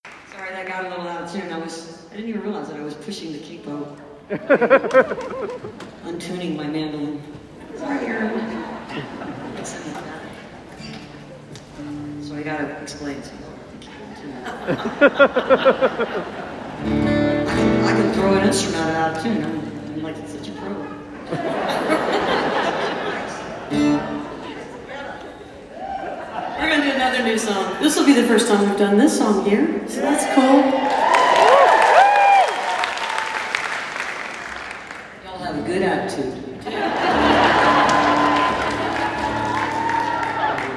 21. talking with the crowd (0:40)